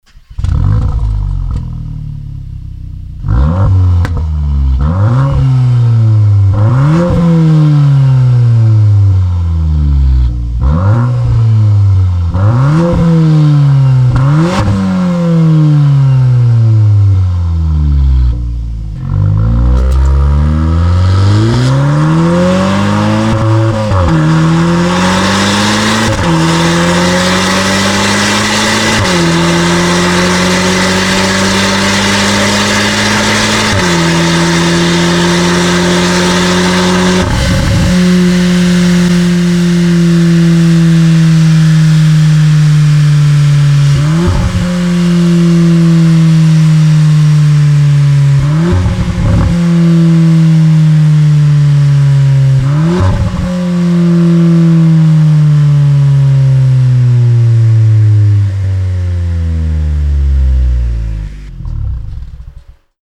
Remus Volkswagen Golf Mk8 R 2.0TSI (2020+) Racing Cat-back Exhaust System (Louder)
VW_Golf_VIII_R_Racing.mp3